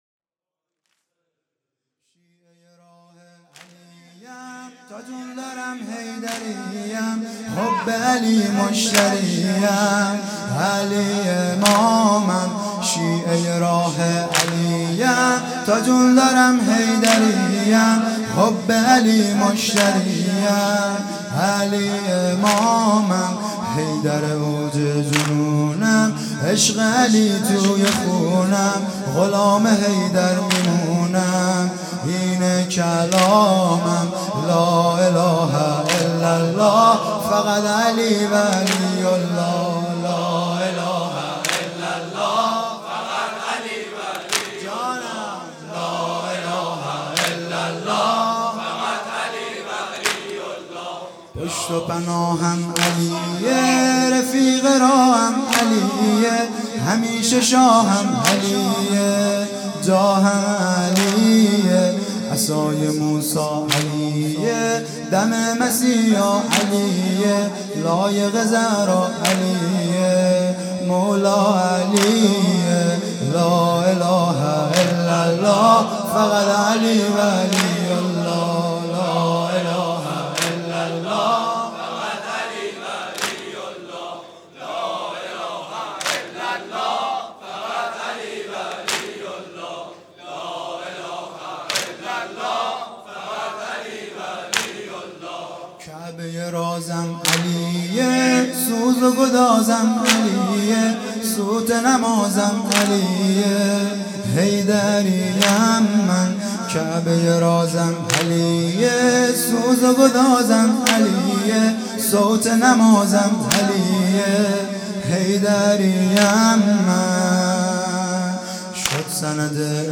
شیعه راه علی ام|شب هشتم محرم ۹۵